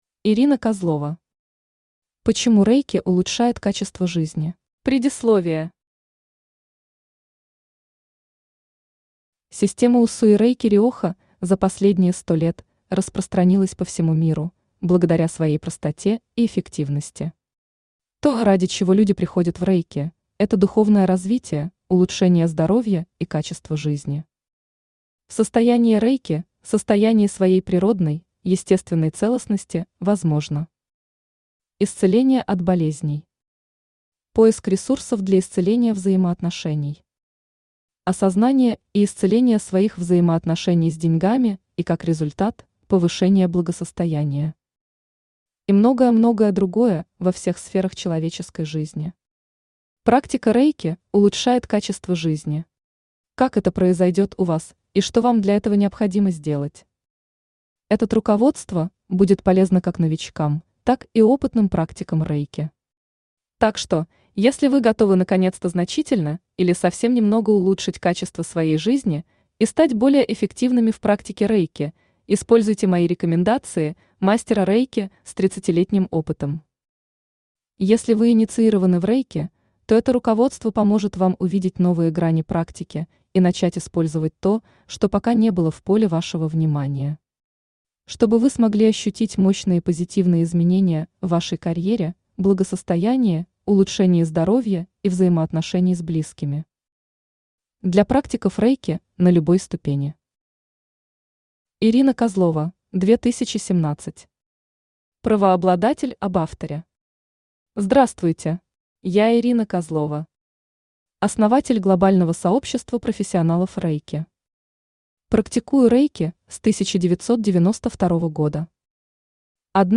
Aудиокнига Почему Рэйки улучшает качество жизни Автор Ирина Александровна Козлова Читает аудиокнигу Авточтец ЛитРес.